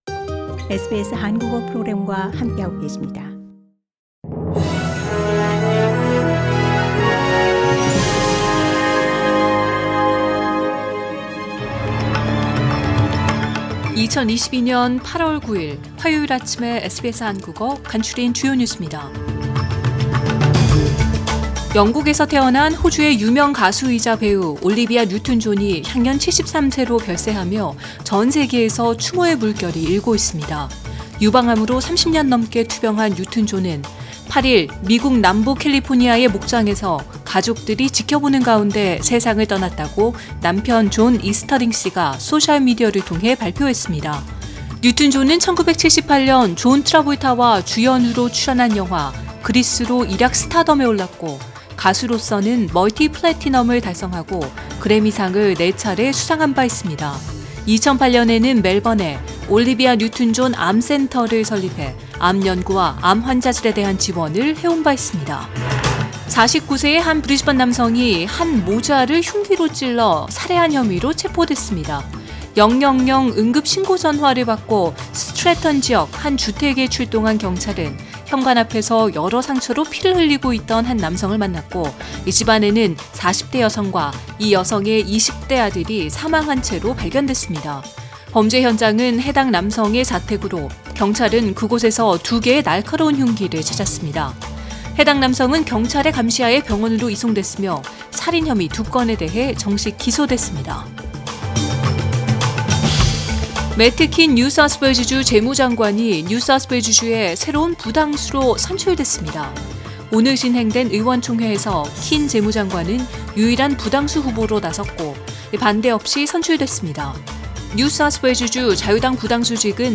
2022년 8월 9일 화요일 아침 SBS 한국어 간추린 주요 뉴스입니다.